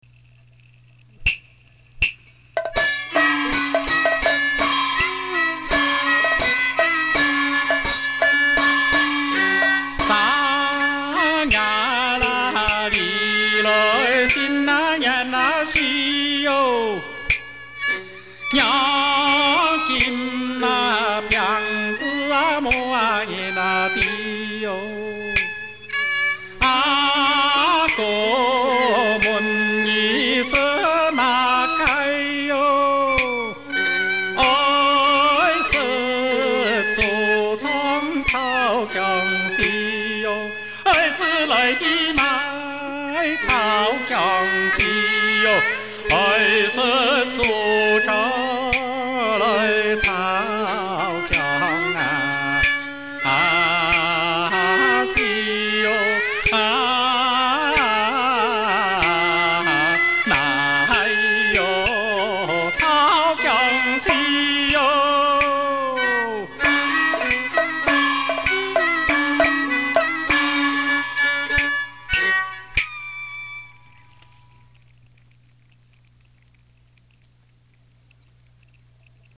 演唱